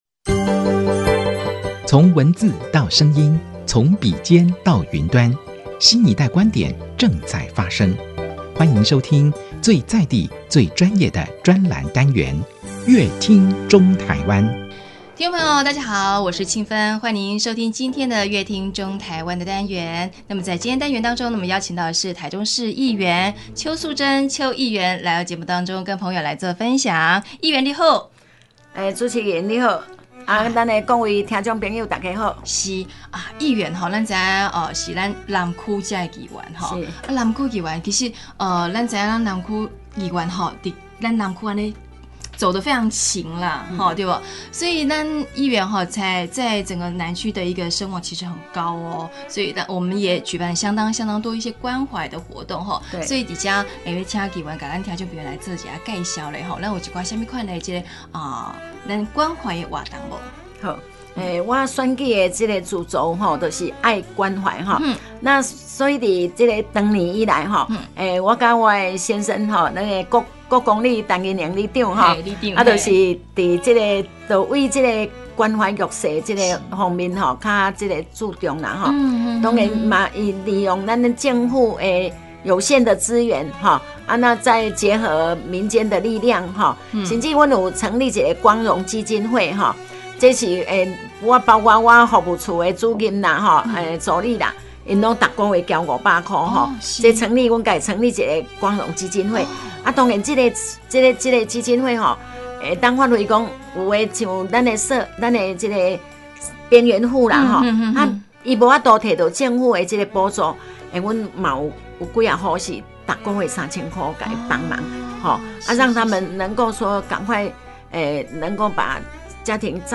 本集來賓：臺中市議員邱素貞 本集主題：「散播溫暖，散播愛」 本集內容： 寒冬中，若有人伸出援手拉困苦者一把，那份關懷與愛彷如甘霖，而正是臺中市議員邱素貞的核心價值。
本集專欄單元邀請邱素貞議員一起分享散播溫暖散播愛的理念與作為。